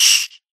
Sound / Minecraft / mob / silverfish / hit1.ogg